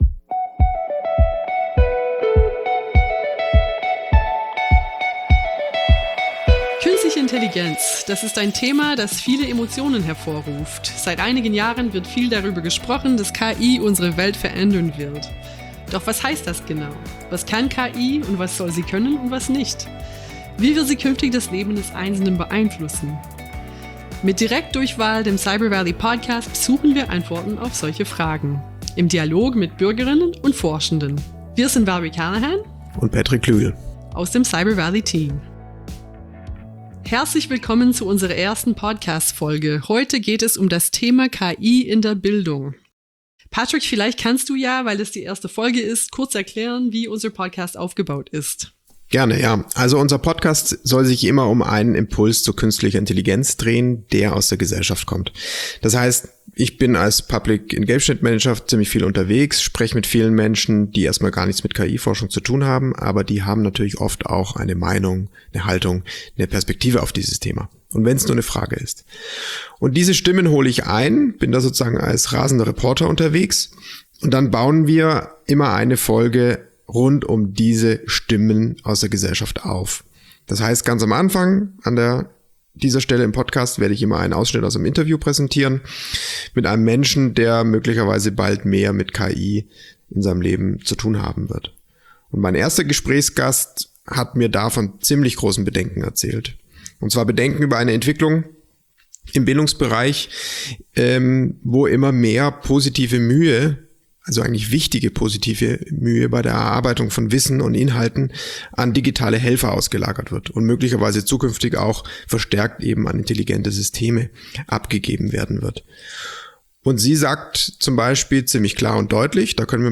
Menschen aus der Region Stuttgart-Tübingen schildern ihre Perspektiven auf das Thema KI und konfrontieren damit Cyber Valley Forschende.